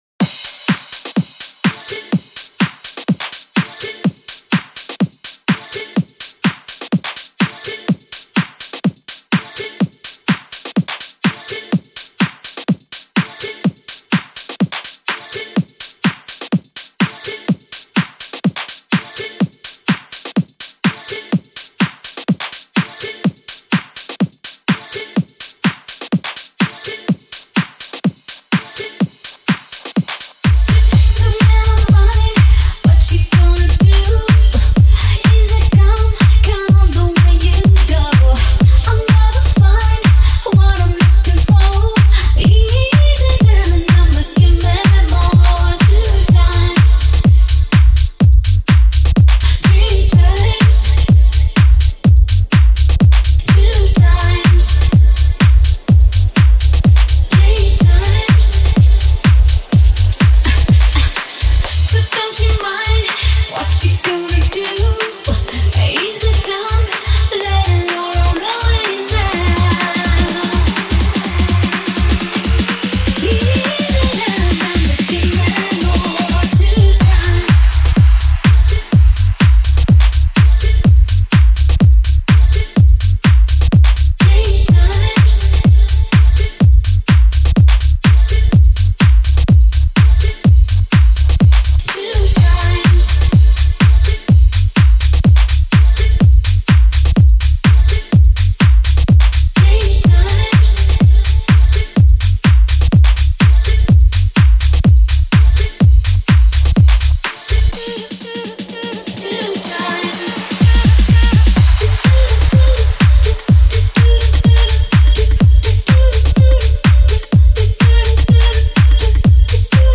He is involved in electronic music, which he also produces.